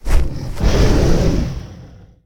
ogg / general / combat / creatures / dragon / he / attack2.ogg
attack2.ogg